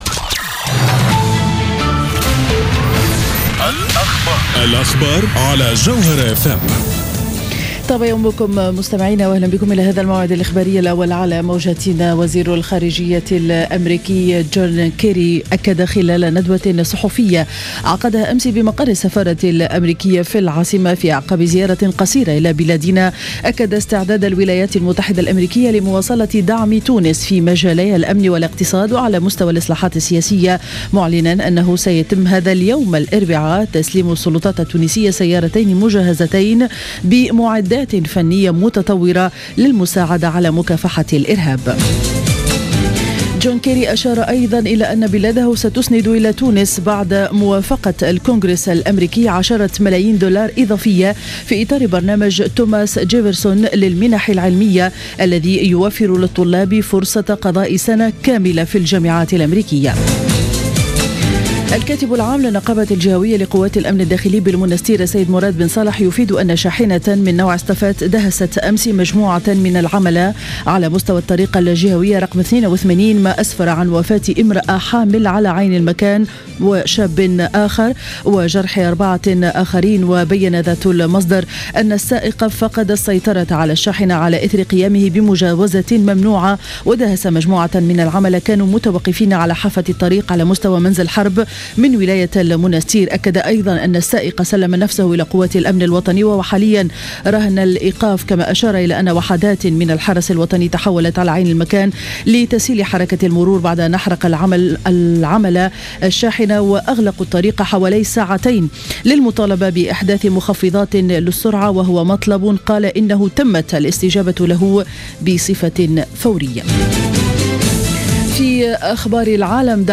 Journal Info 07H00 du 19-02-14